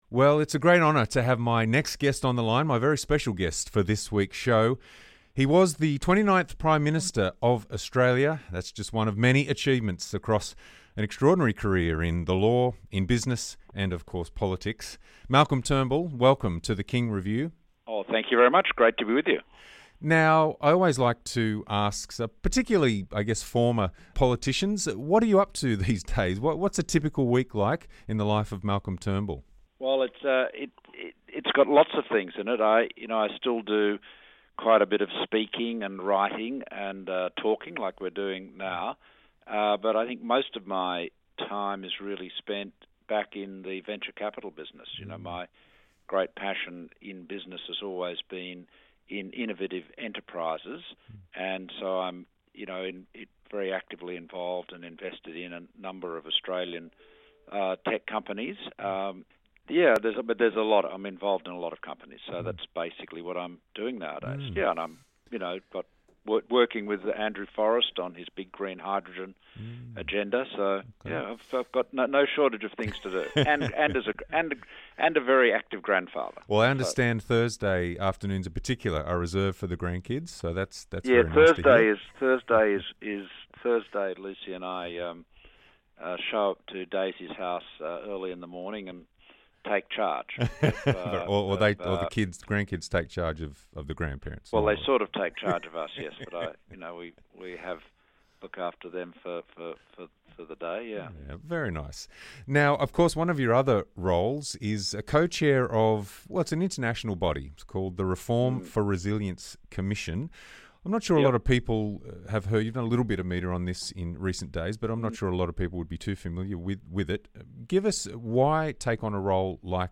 The King Review - an extended interview with the former PM on everything from climate change and government debt to his criticisms of the current Federal Government - 11 June 2021